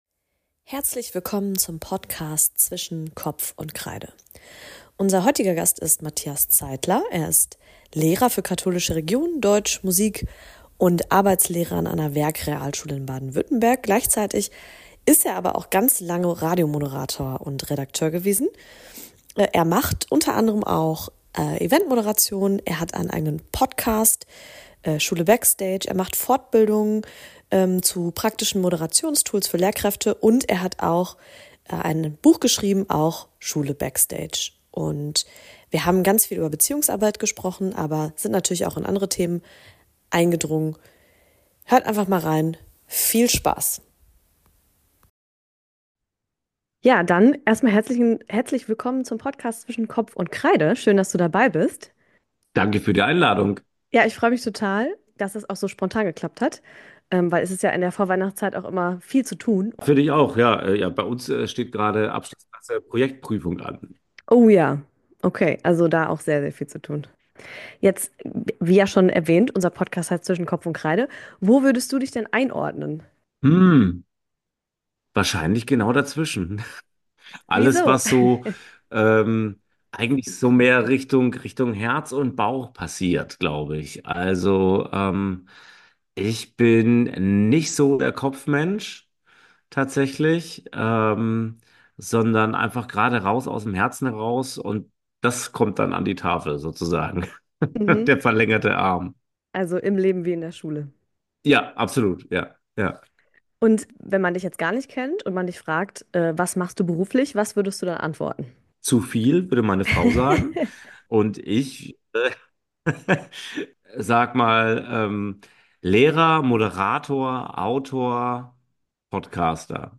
Ein inspirierendes Gespräch über Menschlichkeit im Bildungswesen und die Vision eines zukunftsfähigen Unterrichts.